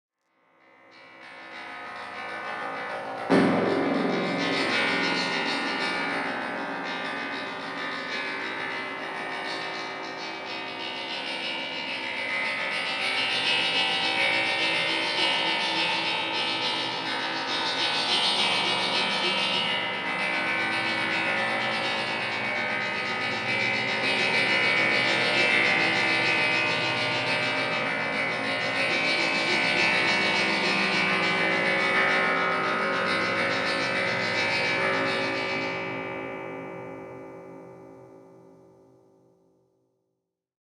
I began using my hands and moved on to other objects such as steel rods, percussion mallets, a prepared music box, magnets and hacksaw blades (Audio 2).